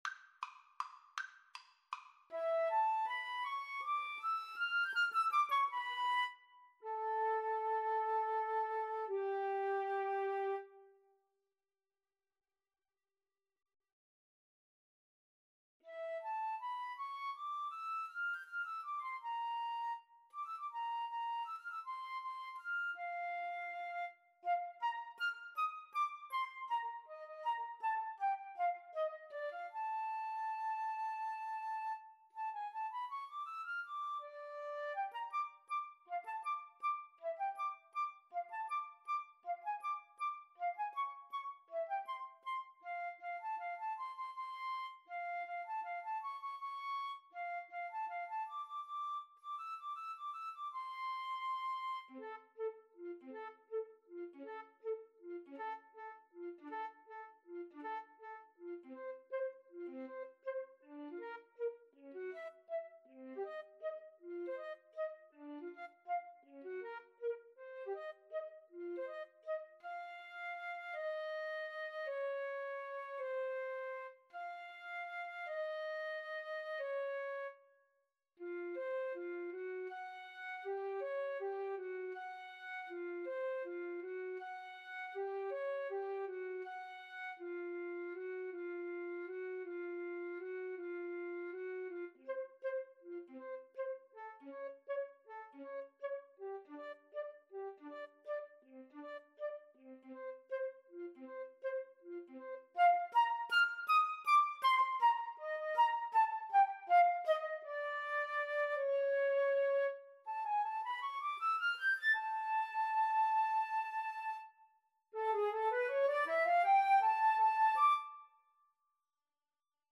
Allegretto = 160
3/4 (View more 3/4 Music)
Classical (View more Classical Flute Duet Music)